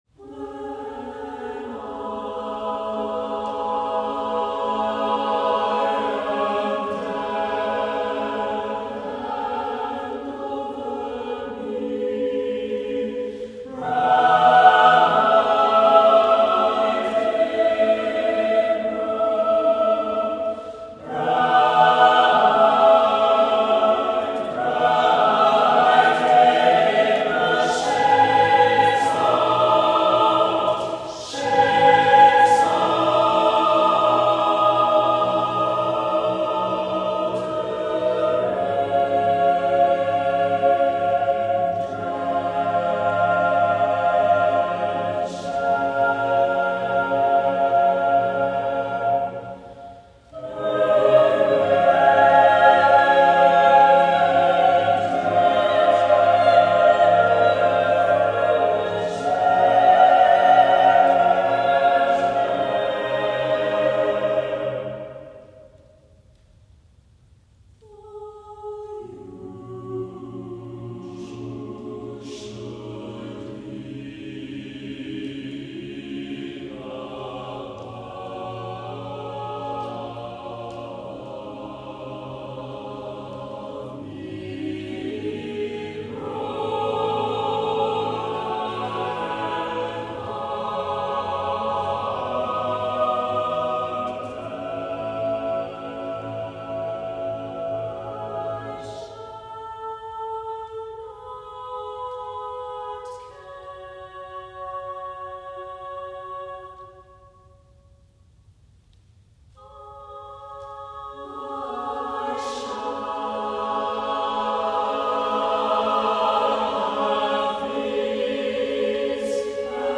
SATB Chorus with Divisi & Treble Soli